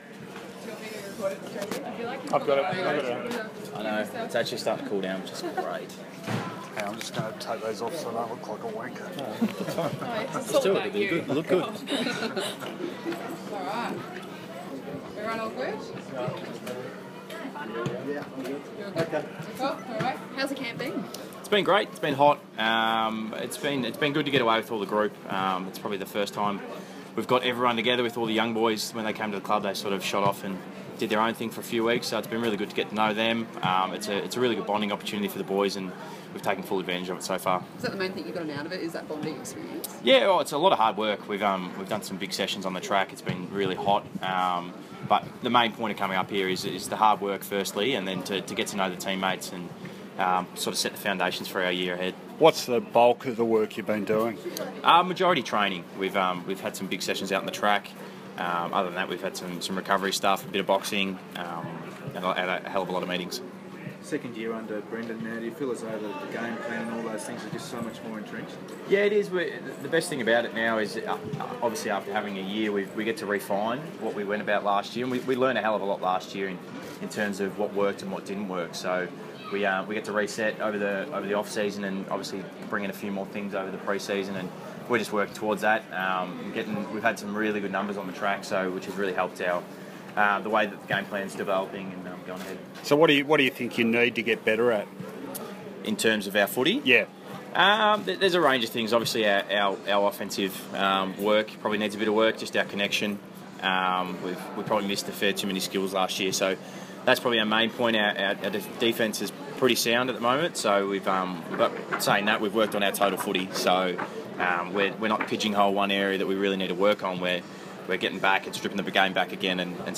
Sam Docherty press conference - December 18
Carlton defender Sam Docherty speaks to the media during the Blues' Gold Coast training camp.